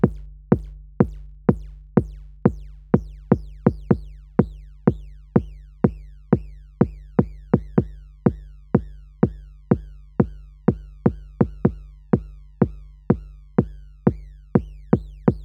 ok here’s the default kick, into comp with specific settings at 100% wet.
i’m adjusting the release of the comp which changes the ‘fall’ speed of these chirps.
low freqs rolled off in audio editor and boosted to make the noise clear.